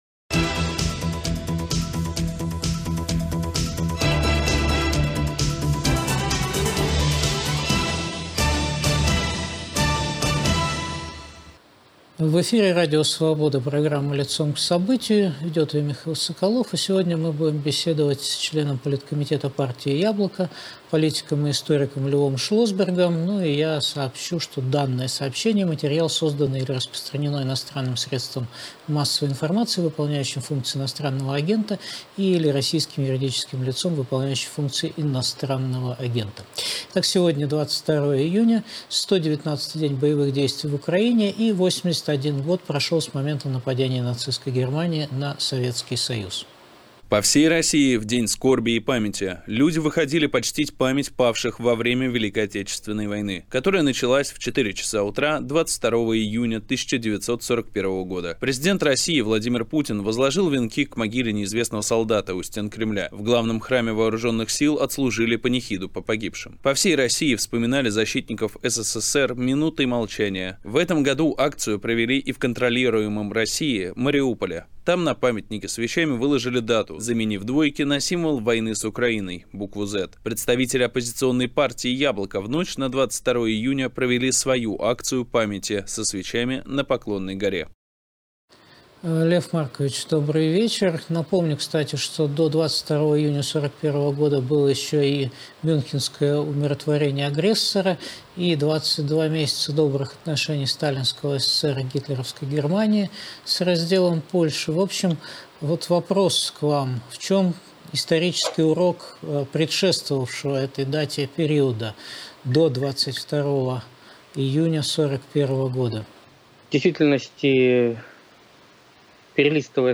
В эфире член Политкомитета партии «Яблоко» Лев Шлосберг.